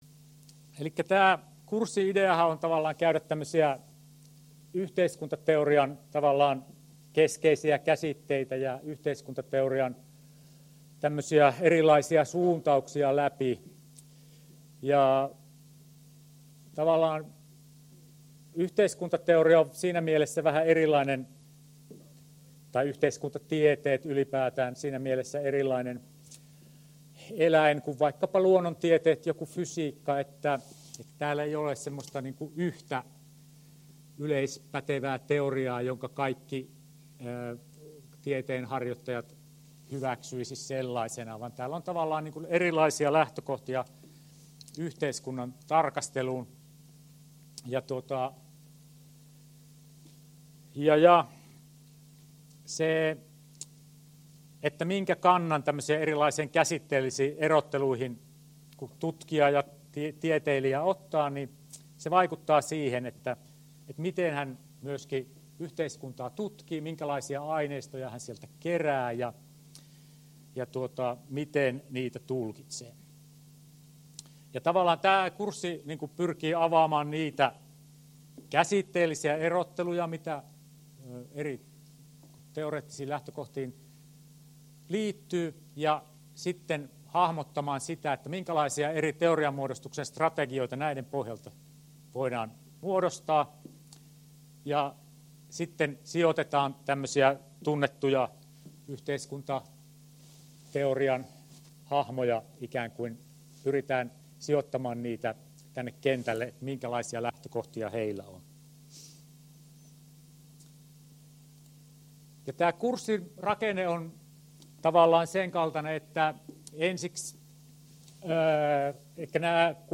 YFIP1003 Luento 1 — Moniviestin